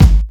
Good Stuff Kick 2.wav